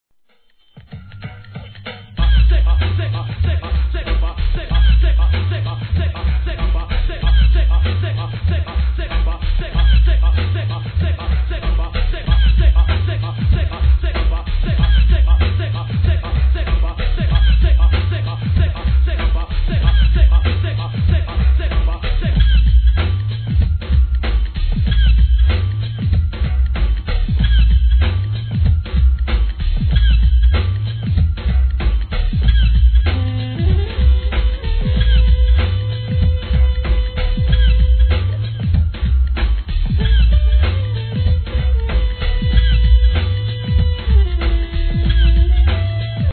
1. HIP HOP/R&B
'90sのいけてるPARTYトラック物!!!